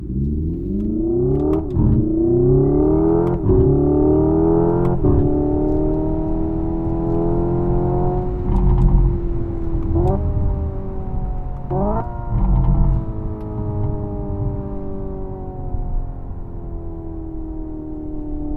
Écoutez le doux son de la performance
Appuyez doucement sur l’accélérateur, instantanément le volume sonore monte !
Hyundai_IONIQ_5_N_Sound_Ignition.mp3